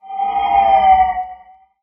buff_icearrow.wav